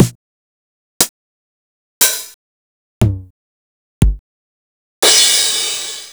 Drum-kit.wav